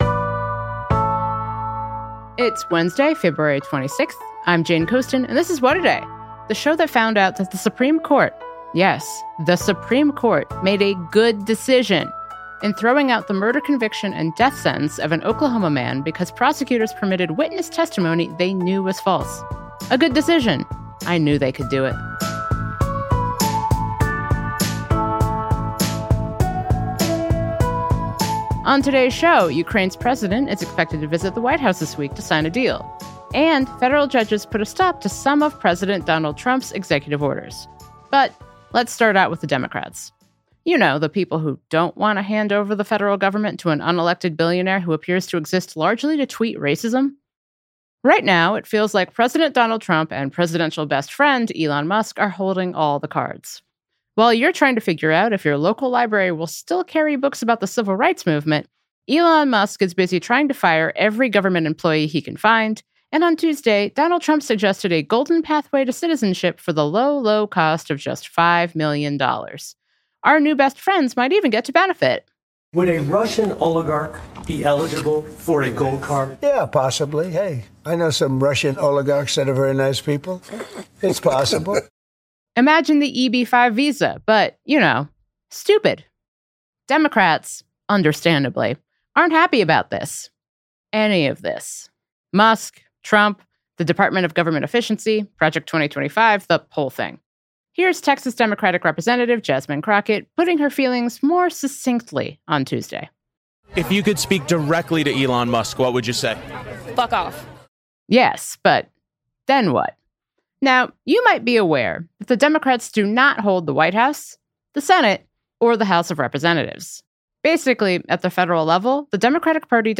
Journalist Matt Yglesias, who writes the ‘Slow Boring’ newsletter on Substack, talks about steps we can all take to